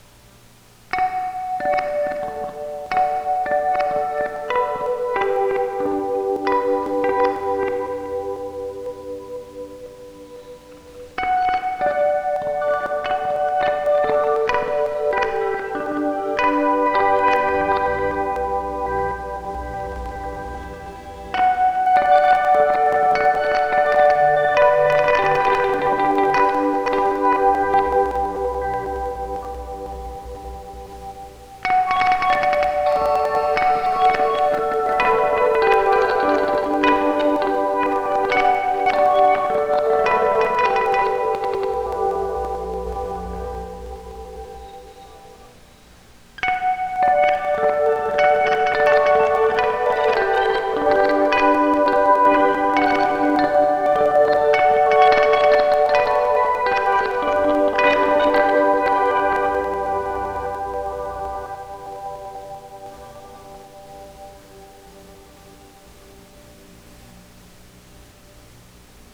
The phrase is repeated with 1) reverse delay 2) reverse+octave 3) reverse+octave+stutter 4) reverse+octave+stutter+5th…